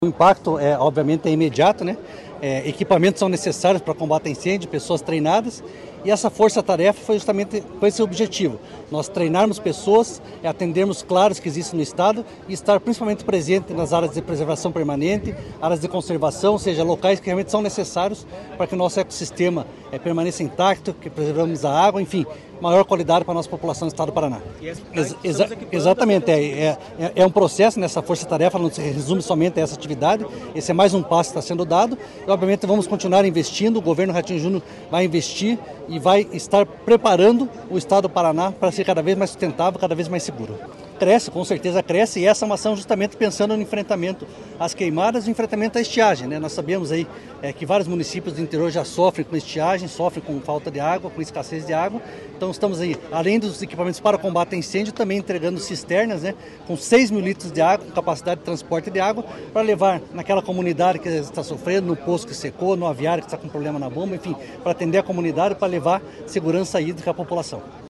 Sonora do coordenador estadual da Defesa Civil, coronel Fernando Schunig, sobre a entrega de equipamentos a 100 municípios para combate a incêndios